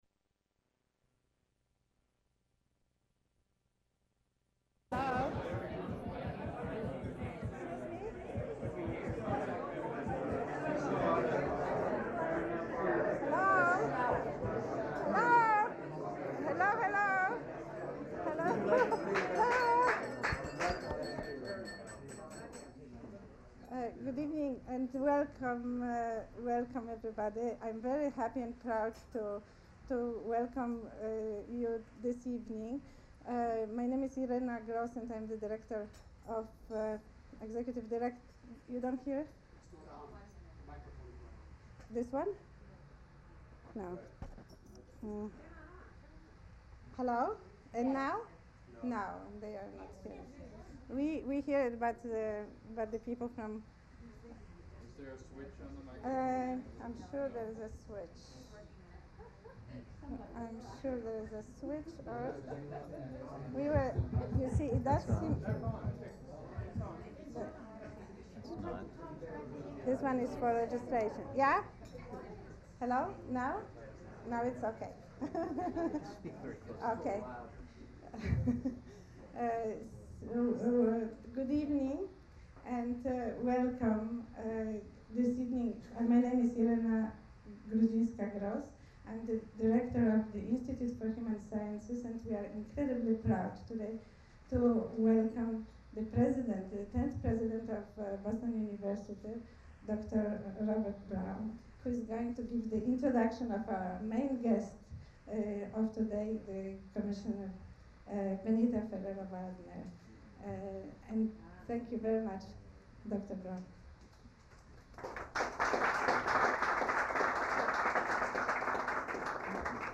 Audio recording of the talk